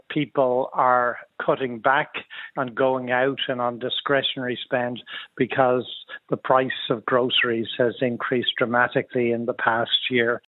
Economist